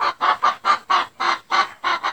Added chicken sounds to the chickens.